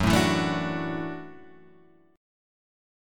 F# Minor 7th Sharp 5th